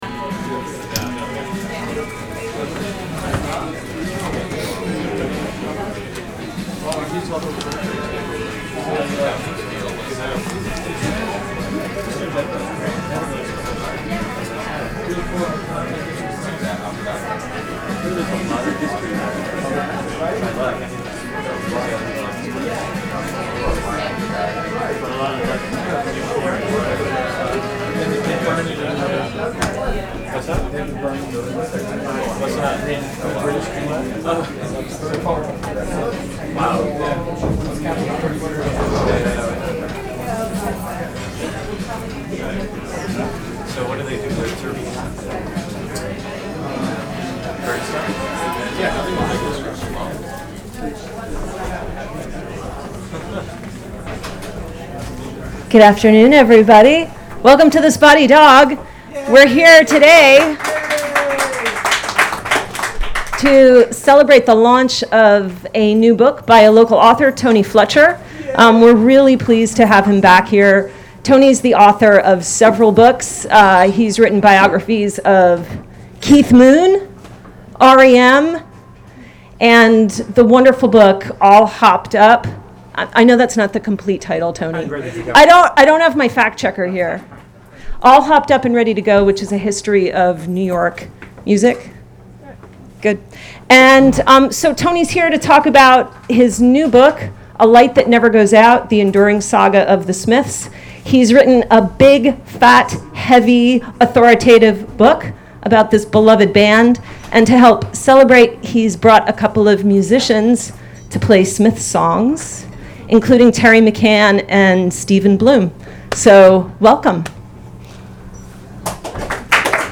at Spotty Dog Books & Ale.